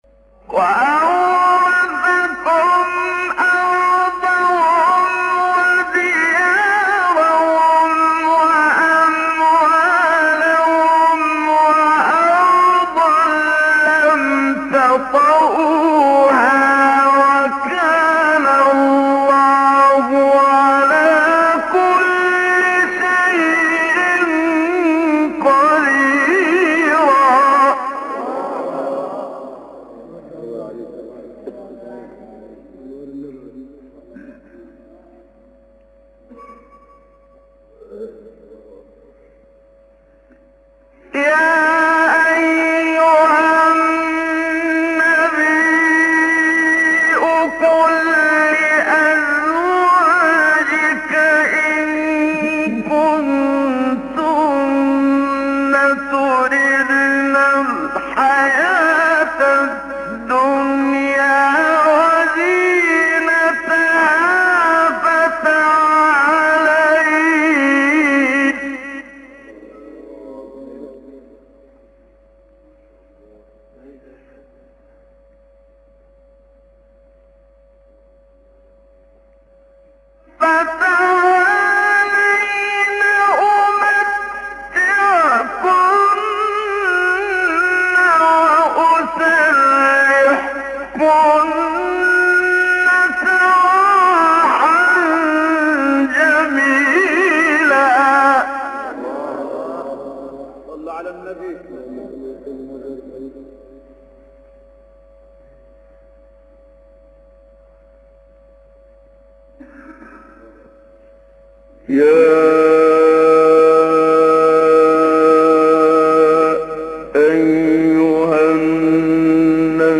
سوره : احزاب آیه: 27-29 استاد : محمد صدیق منشاوی مقام : نهاوند قبلی بعدی